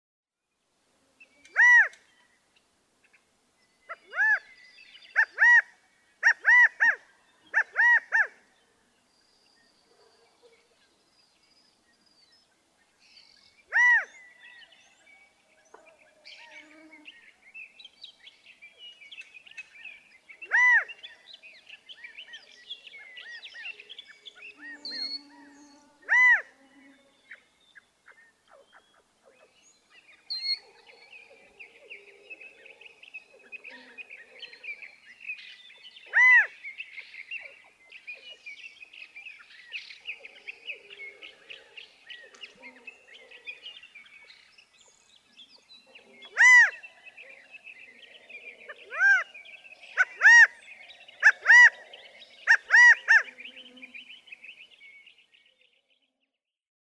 California quail
Whether call or song, the chi-CAH-go is believed to be inborn, or innate, not learned or imitated by listening to adult models of the same species.
Malheur National Wildlife Refuge, Burns, Oregon.
112_California_Quail.mp3